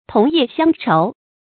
同業相仇 注音： ㄊㄨㄙˊ ㄧㄜˋ ㄒㄧㄤ ㄔㄡˊ 讀音讀法： 意思解釋： 猶言同行是冤家。